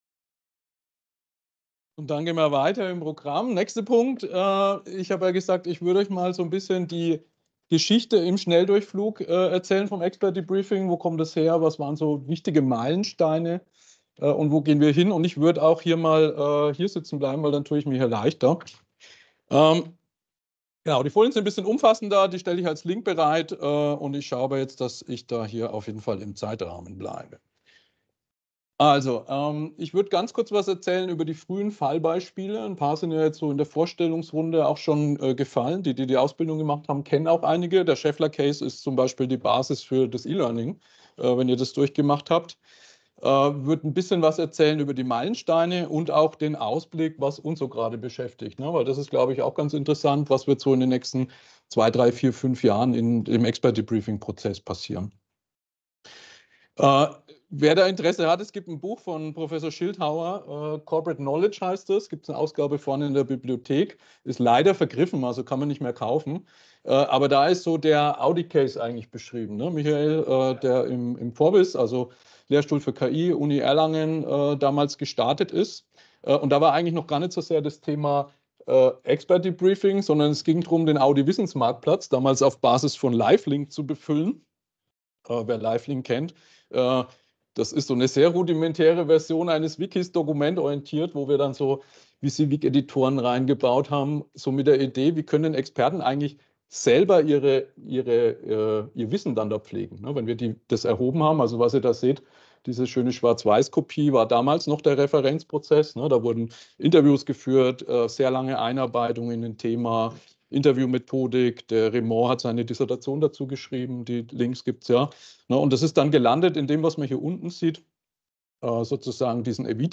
Beschreibung vor 2 Monaten Das Expert Debriefing hat sich von einem einfachen Wissenstransferprozess zu einem umfassenden System entwickelt, das heute über 950 ausgebildete Moderatoren umfasst und durch KI-Integration neue Dimensionen erreicht. Der Vortrag zeigt die Evolution von den frühen Fallbeispielen bei Audi und Schaeffler bis hin zu modernen digitalen Lösungen und gibt einen Ausblick auf zukünftige Entwicklungen mit Künstlicher Intelligenz.